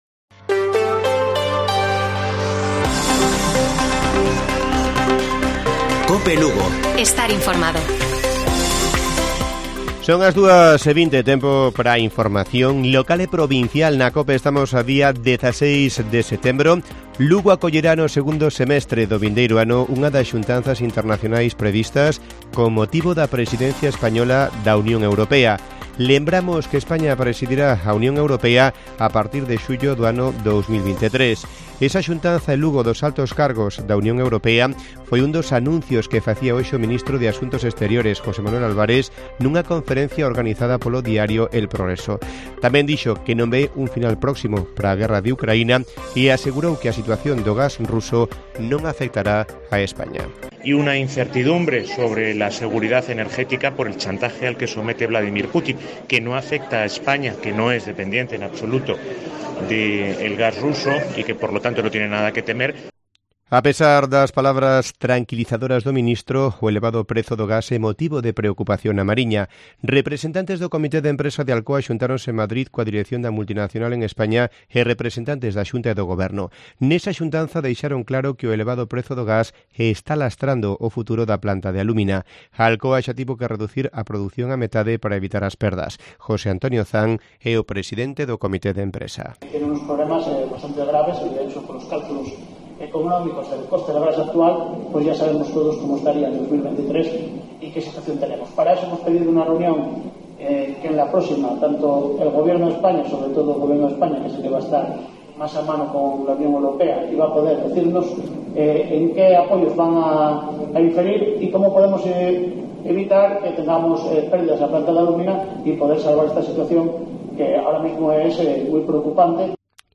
Informativo Mediodía de Cope Lugo. 16 DE SEPTIEMBRE. 14:20 horas